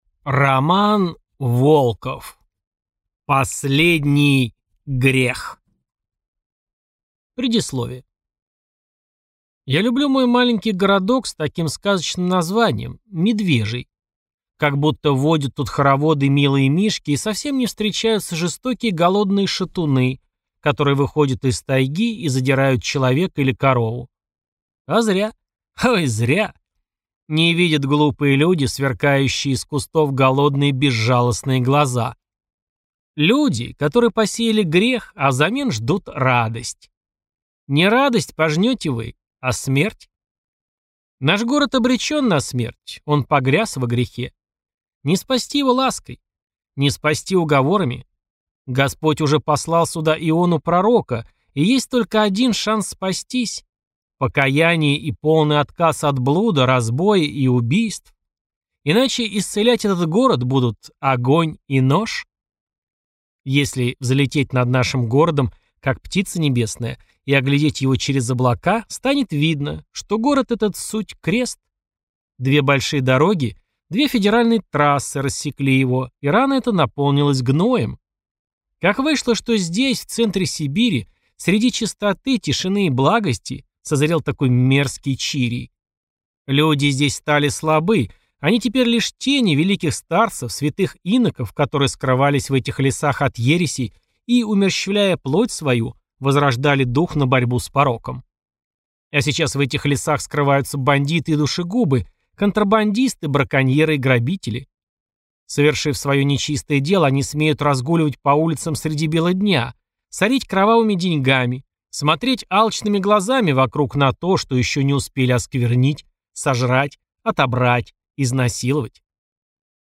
Аудиокнига Последний грех | Библиотека аудиокниг